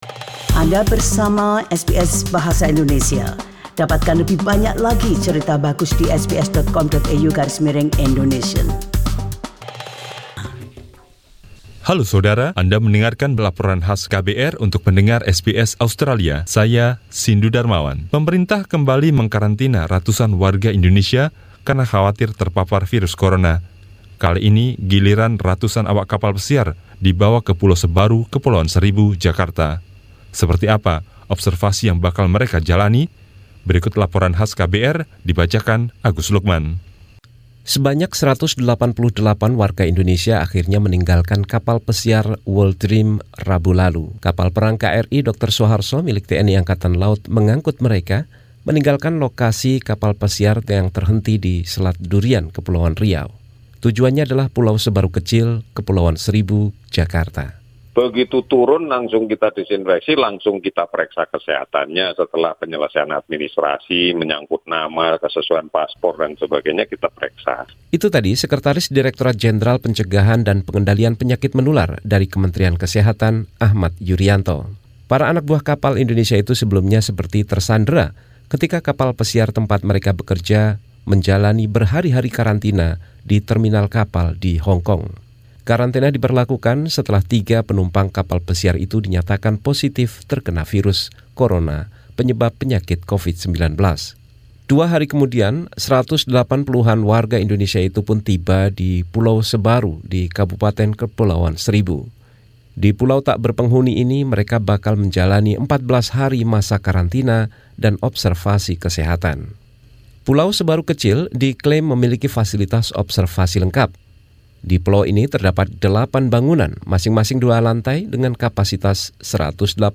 Representatives for the authorities and the participants describe the program.